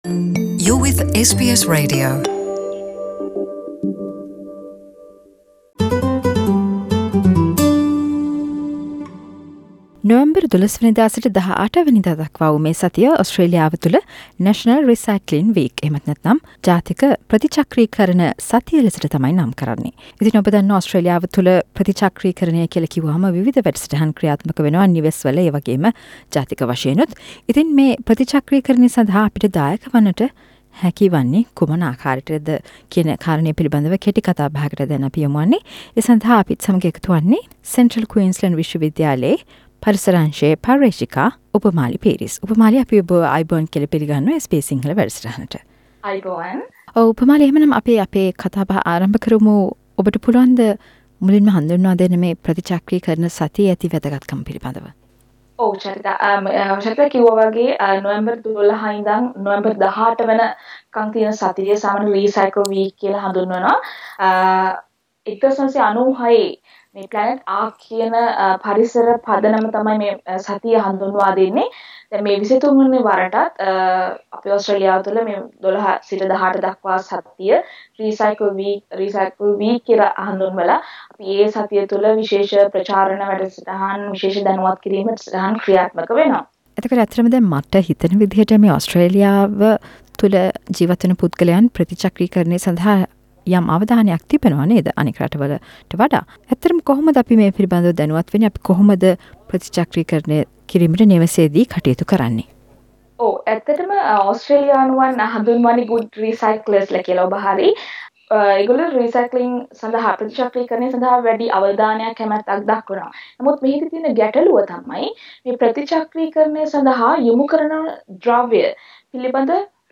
සාකච්චාවට